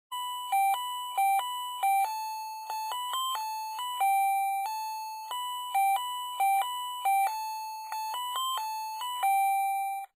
ZVONČEK 16 MELÓDIÍ
• elektronický
• zvuk: 16 striedajúcich sa druhov melódií